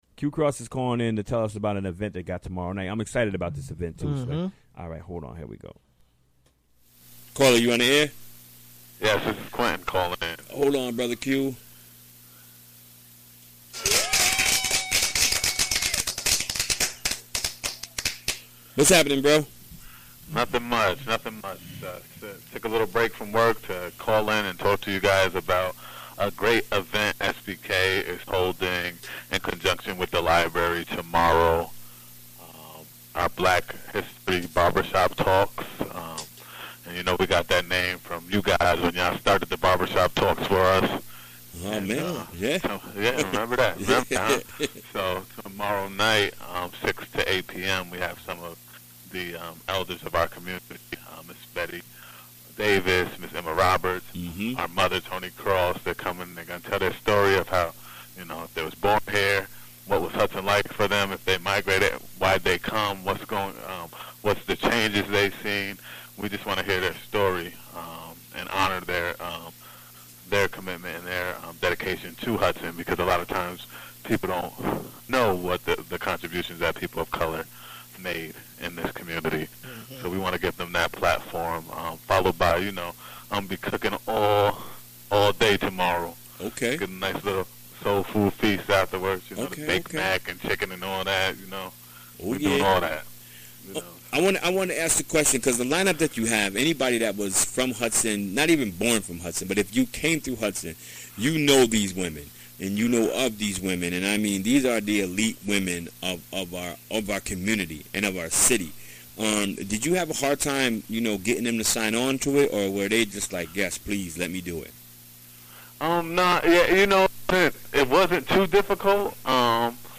Recorded during the WGXC Afternoon Show Wednesday, February 7, 2018.